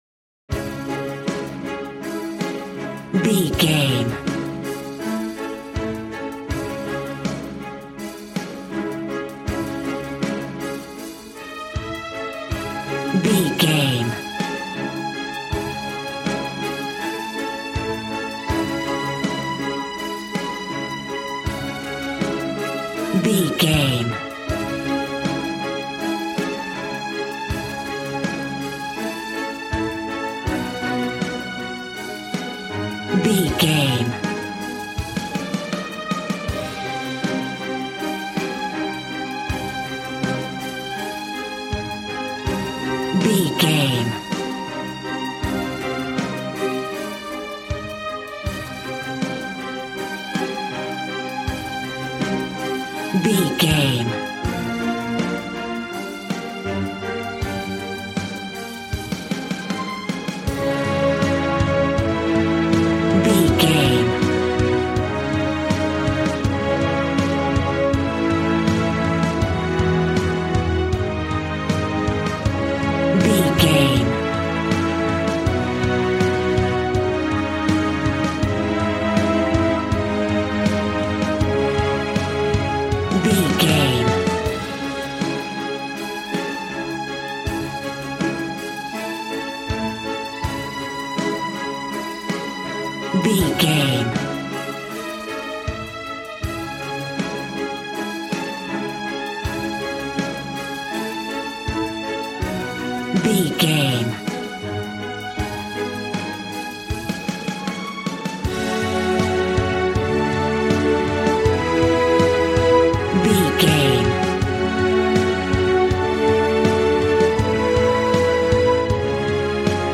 Aeolian/Minor
D♭
dramatic
epic
strings
violin
brass